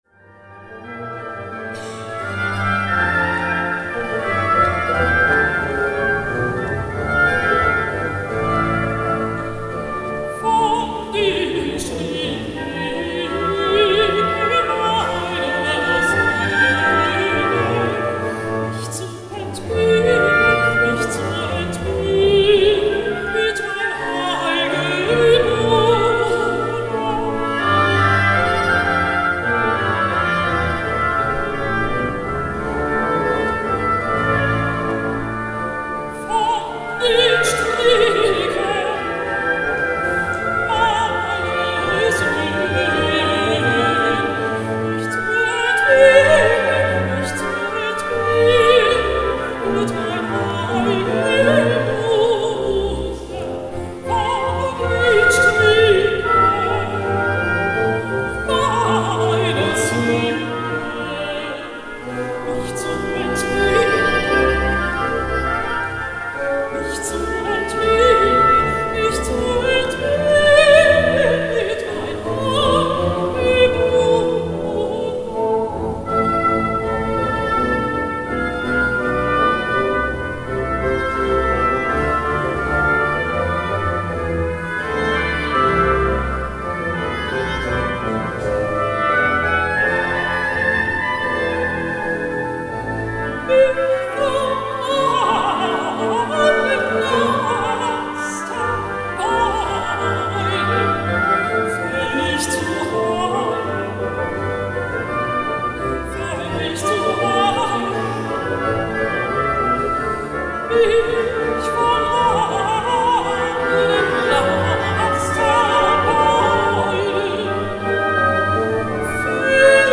Altistin
‣ Arie "Von den Stricken"
(Liveaufnahme aus der Frauenkirche, Dresden)